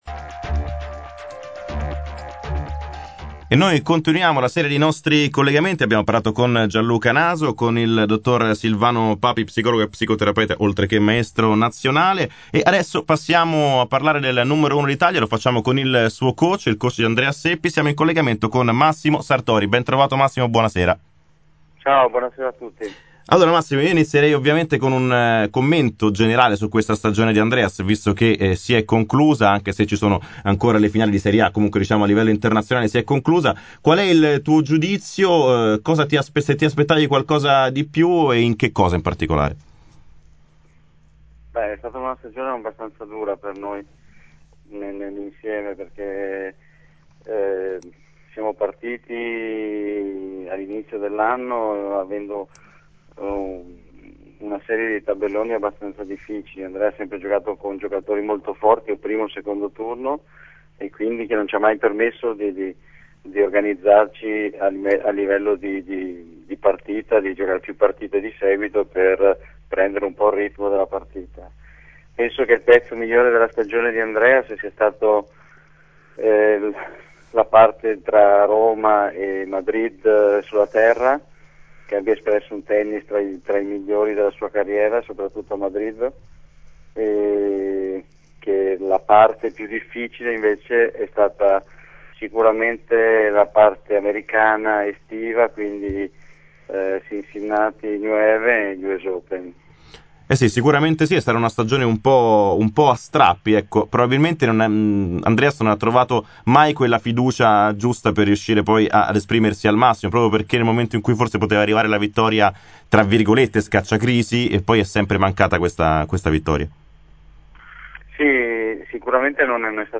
Ascoltate le replica della dodicesima puntata di Spazio Tennis, andata in onda ieri sera sulle frequenze di Nuova Spazio Radio Elle RadioTv.